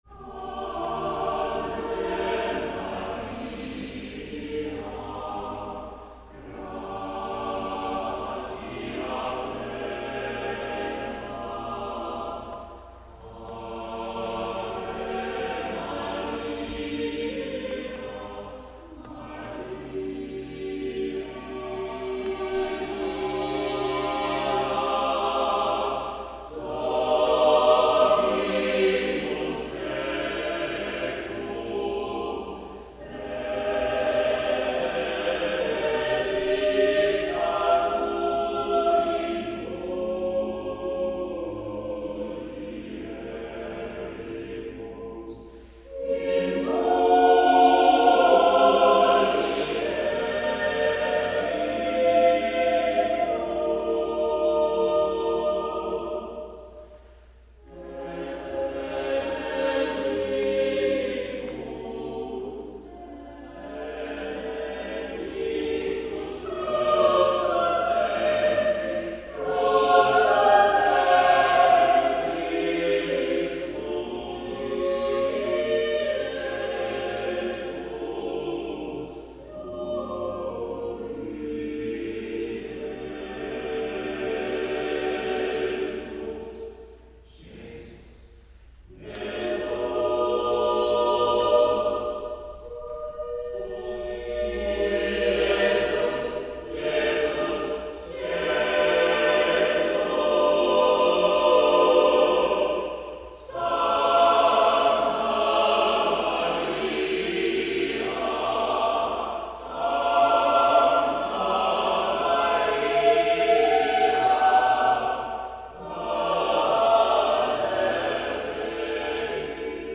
Voicing: SSATTB